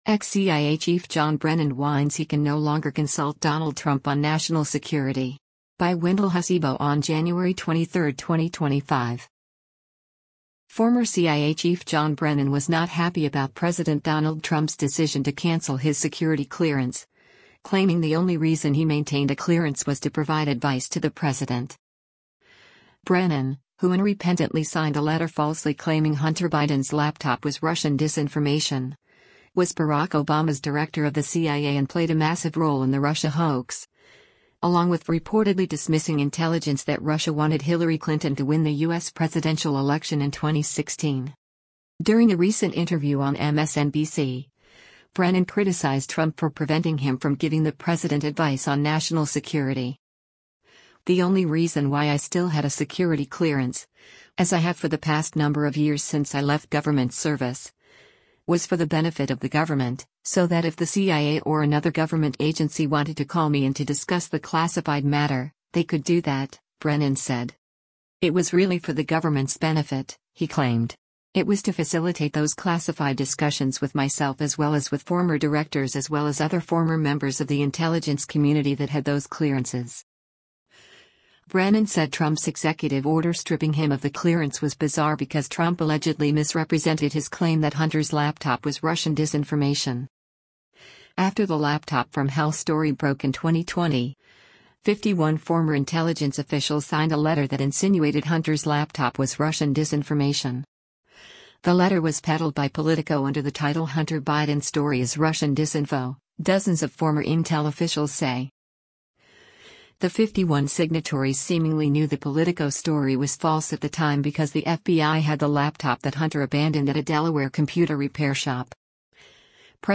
During a recent interview on MSNBC, Brennan criticized Trump for preventing him from giving the president advice on national security.